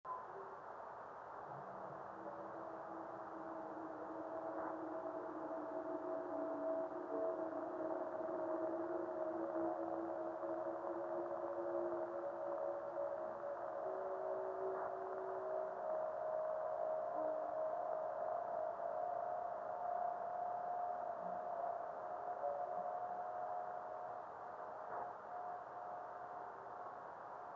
Sound precedes the visual meteor by a second or two.